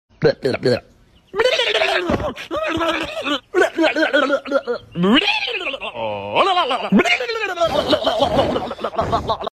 crazy BLE BLE goat daily sound effects free download